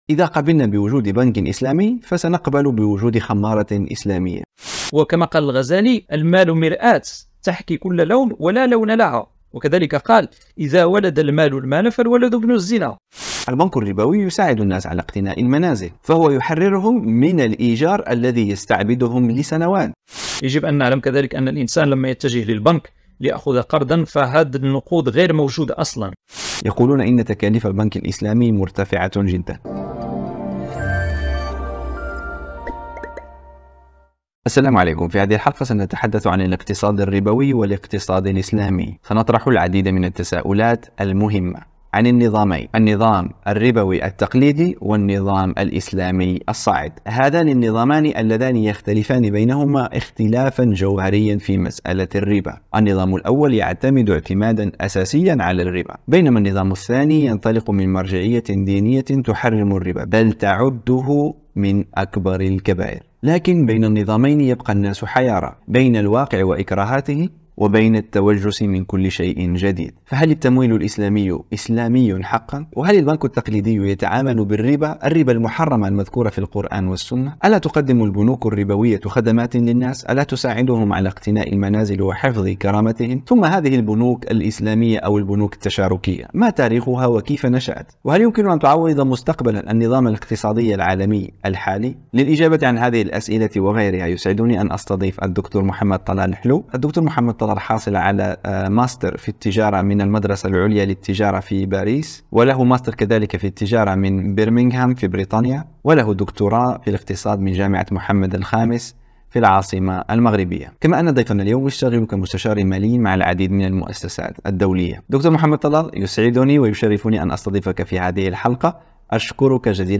خطورة النظام الربوي وآفاق الاقتصاد الإسلامي | حوار